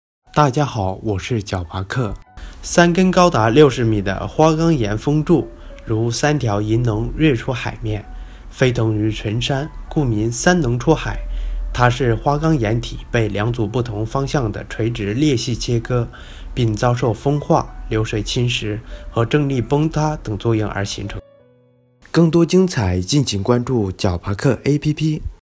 三龙出海----- 呼呼呼～～～ 解说词: 三根高达60米的花岗岩峰柱，如三条银龙跃出海面，飞腾于群山，故名“三龙出海”。